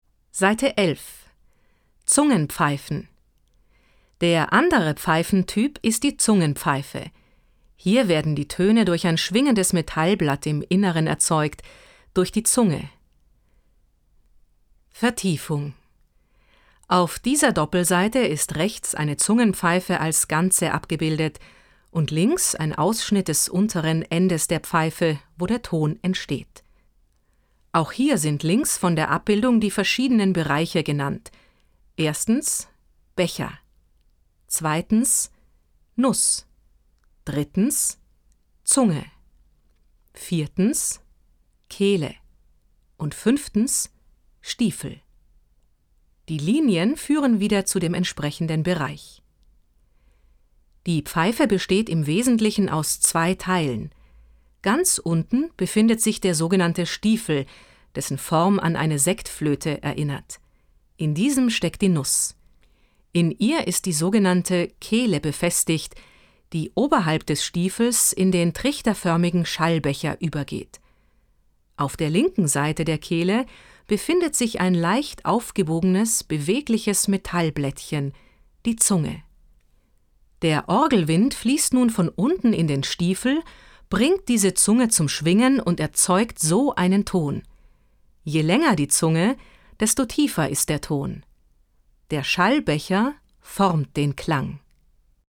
Interaktive Elemente, Hörtext mit Musikbeispielen